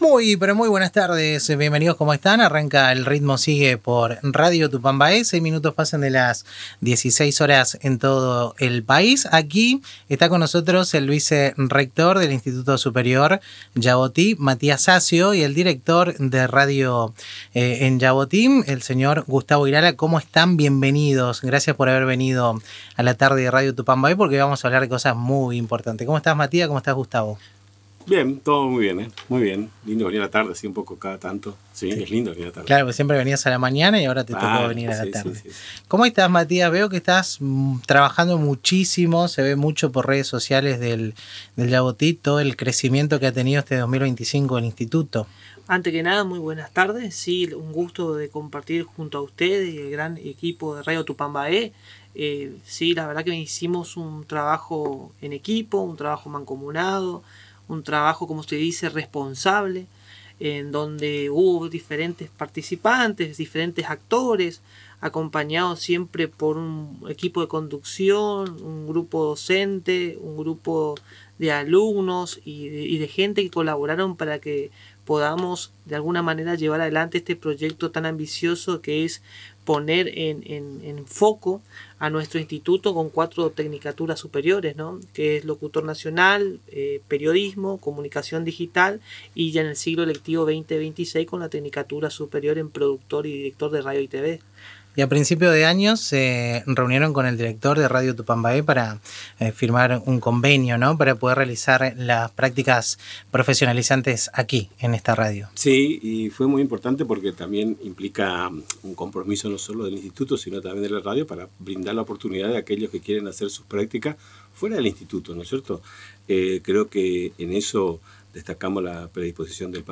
en una entrevista brindada en los estudios de Radio Tupambaé FM 105.9